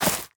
latest / assets / minecraft / sounds / block / roots / step1.ogg